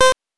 8 bits Elements
beep_4.wav